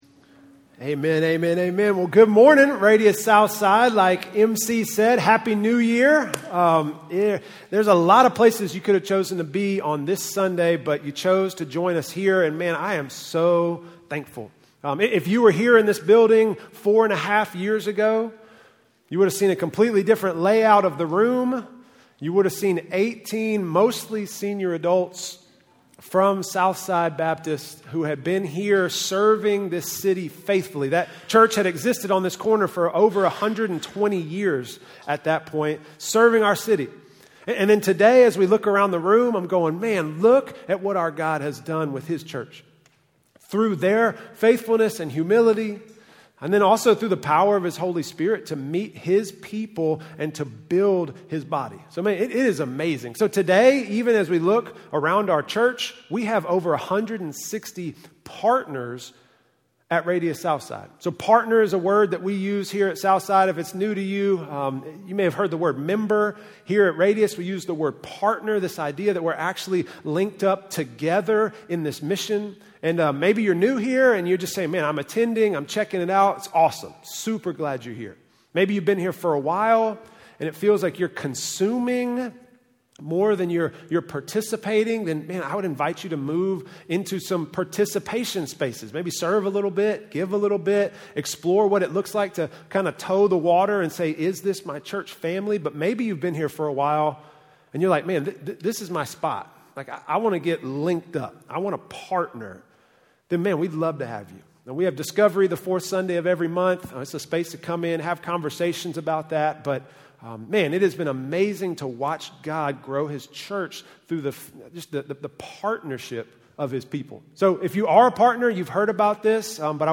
From Campus: "RADIUS Southside"